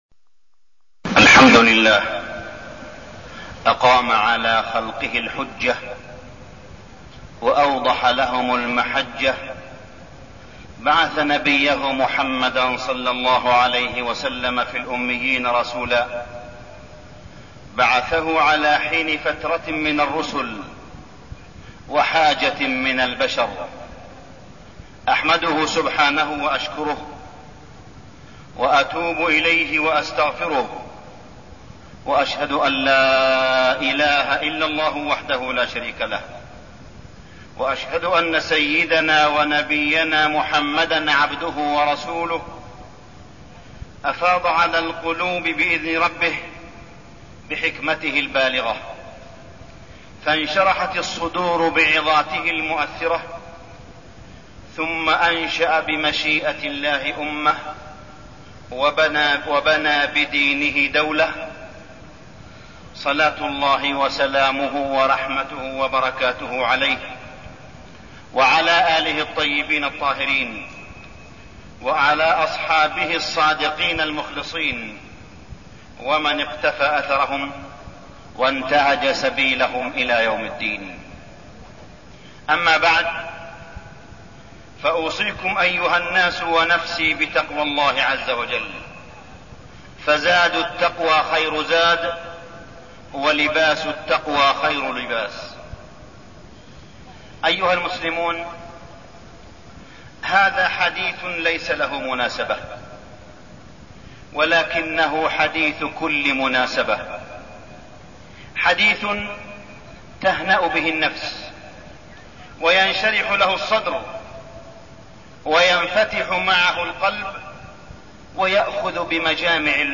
تاريخ النشر ٢٣ جمادى الأولى ١٤١٥ هـ المكان: المسجد الحرام الشيخ: معالي الشيخ أ.د. صالح بن عبدالله بن حميد معالي الشيخ أ.د. صالح بن عبدالله بن حميد سيرة النبي صلى الله عليه وسلم